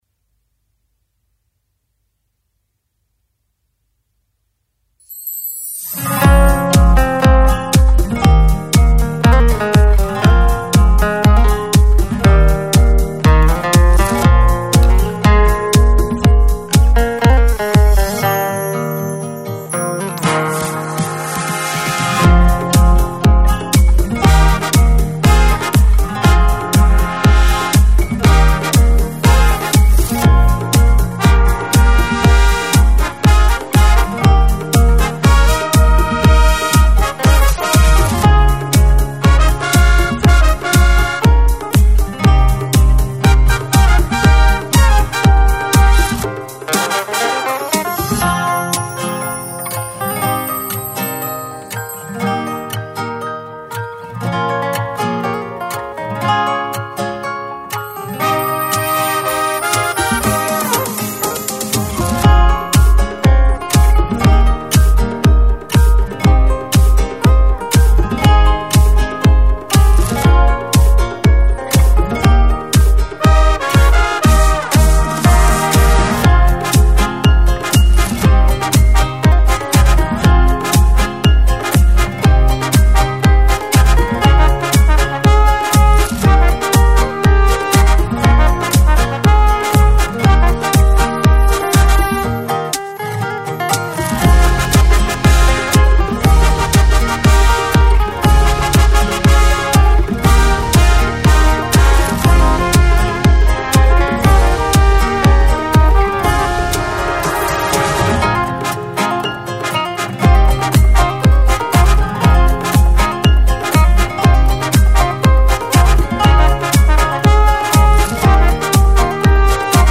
بی‌کلام
آهنگ این اثر توسط این گروه بازخوانی شده است.